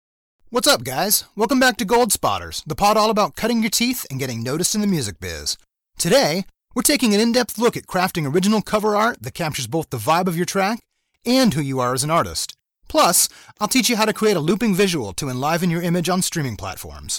Podcast Voice Over Talent | Professional Voice for Podcast
I have a very smooth clear voice.
0904New_Podcast_Intro_Mastered_Final.mp3